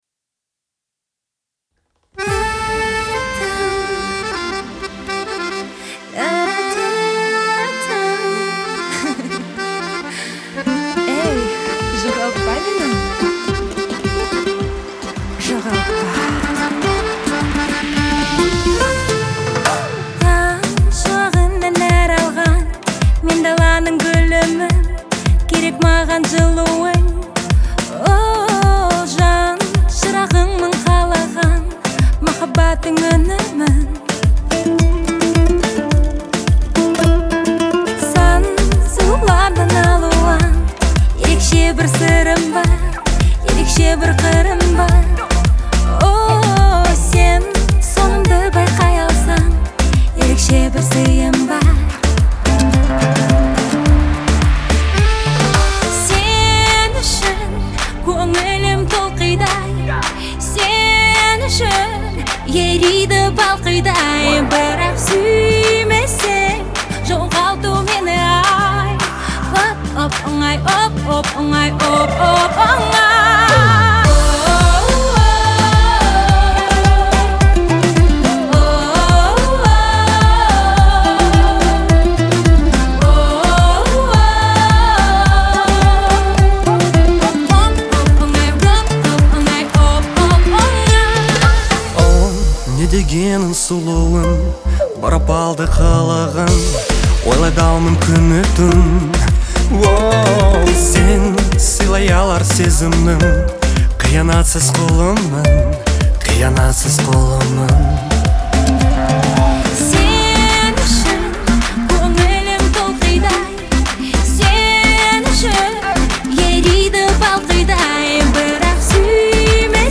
яркая и энергичная композиция